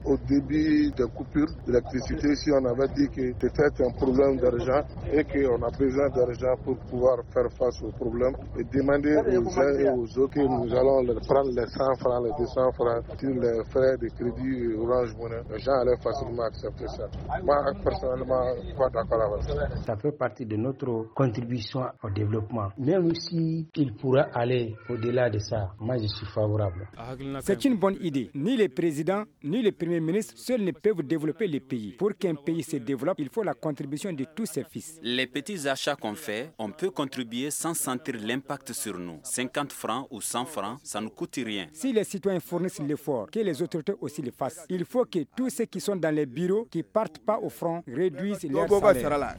VOX-POP-CONFERENCE-PM.mp3